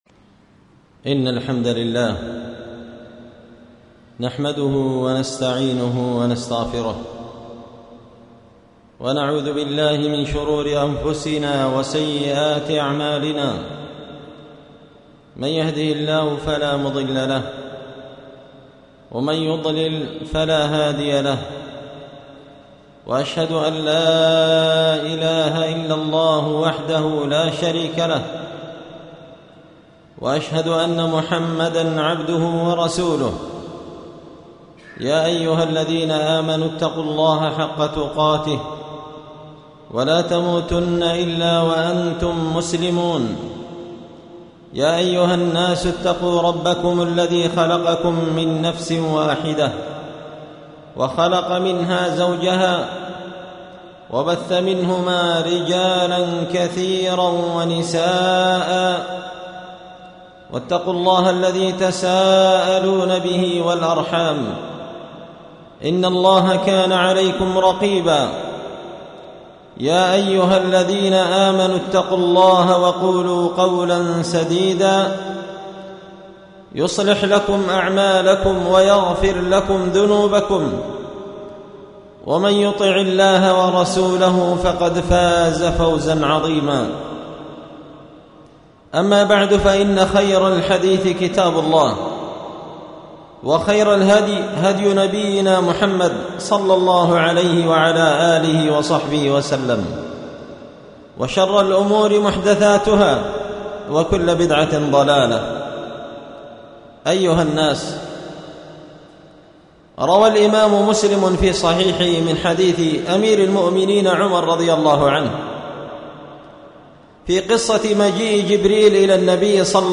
خطبة جمعة بعنوان:
ألقيت هذه الخطبة في مسجد الجامع نشطون-المهرة-اليمن